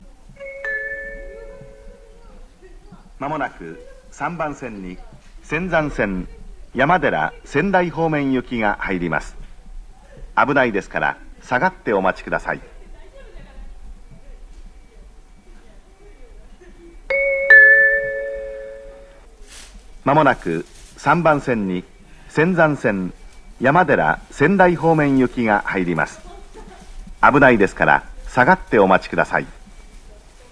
アナウンスは上りが男声、下りが女性となっています。
上り接近放送(山寺・仙台方面)